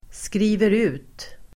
Uttal: [skri:ver'u:t]